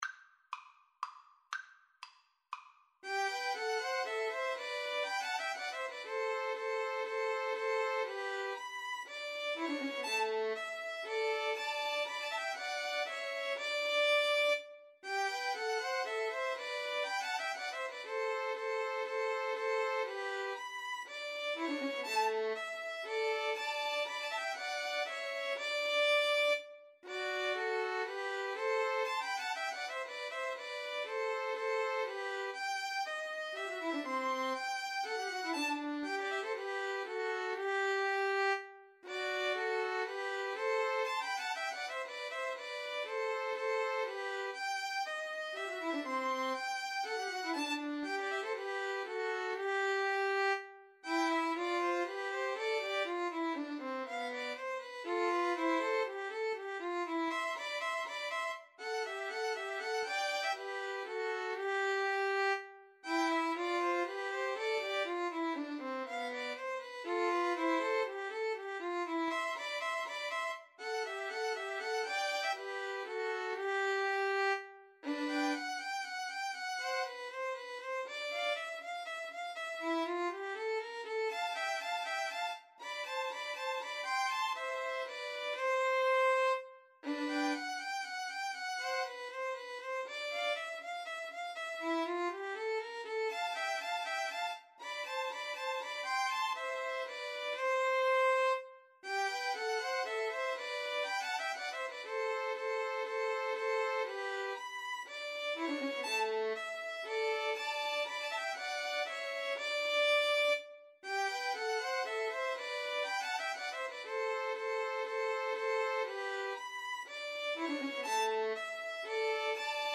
G major (Sounding Pitch) (View more G major Music for 2-Violins-Cello )
3/4 (View more 3/4 Music)
2-Violins-Cello  (View more Intermediate 2-Violins-Cello Music)
Classical (View more Classical 2-Violins-Cello Music)